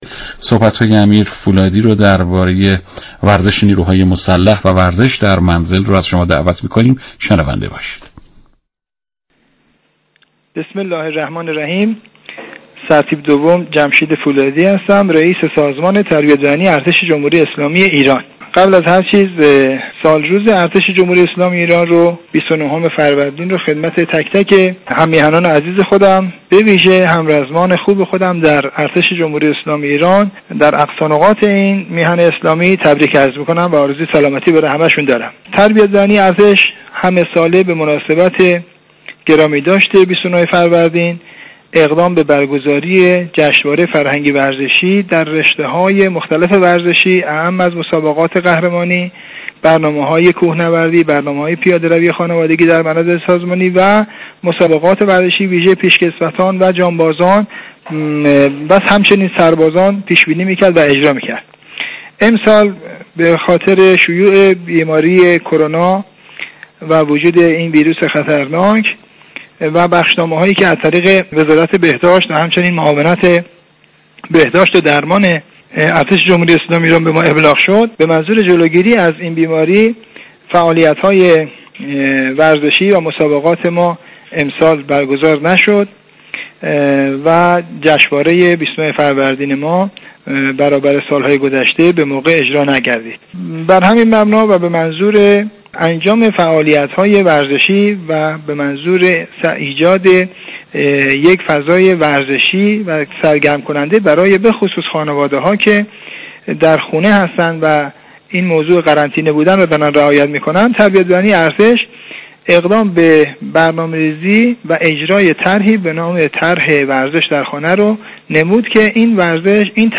رئیس سازمان تربیت بدنی ارتش جمهوری اسلامی ایران در گفتگو با رادیو ورزش در ارتباط با تدوین طرح ورزش در منزل به مناسب سالروز ارتش جمهوری اسلامی ایران با رادیو ورزش توضیحاتی را بیان كرد.